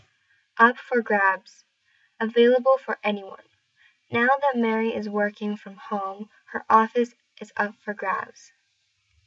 選挙や仕事のポジション、賞金、顧客やマーケットなど、競争の対象になる物に対して使われます。 英語ネイティブによる発音は下記のリンクをクリックしてください。